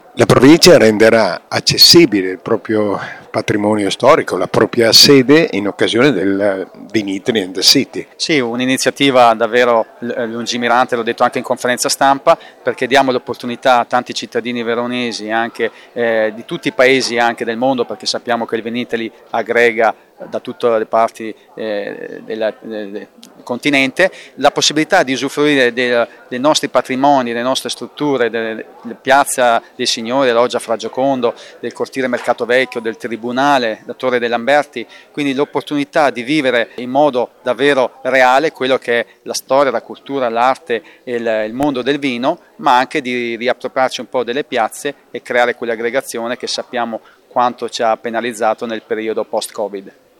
Di seguito le interviste